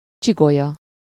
Ääntäminen
Ääntäminen France: IPA: [vɛʁ.tɛbʁ] Haettu sana löytyi näillä lähdekielillä: ranska Käännös Ääninäyte 1. csigolya Suku: f .